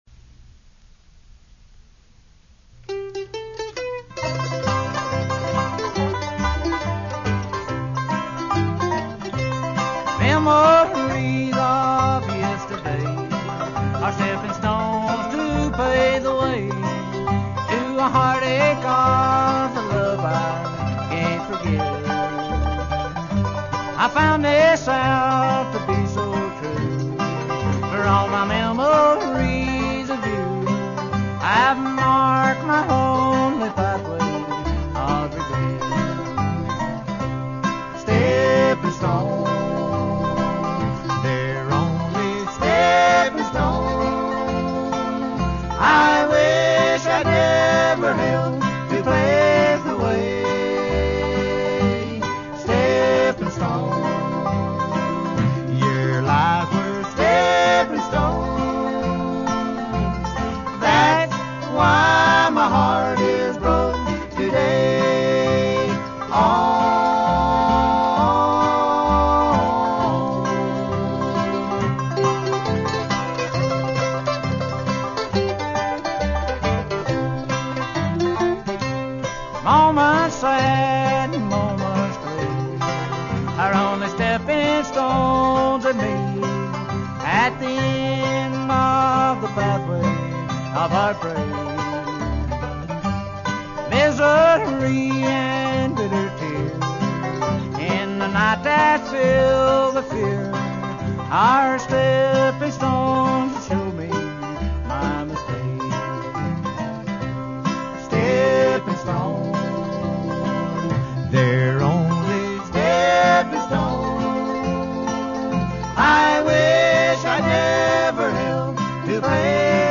mandolin
banjo
guitar
upright bass.